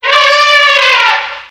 explode1.wav